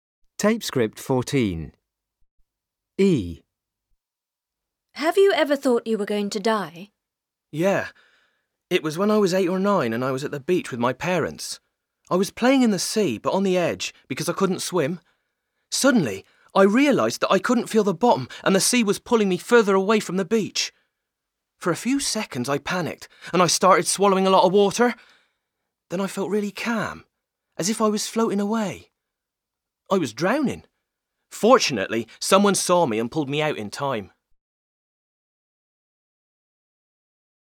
Dialog - direct link Dialog - Alternative link Words Ben